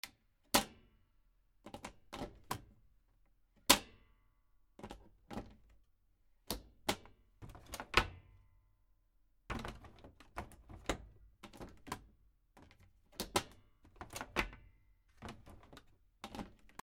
/ M｜他分類 / L10 ｜電化製品・機械
二槽式洗濯機の脱水機の内蓋と外蓋